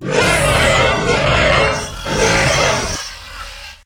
sounds / monsters / chimera / die_1.ogg
die_1.ogg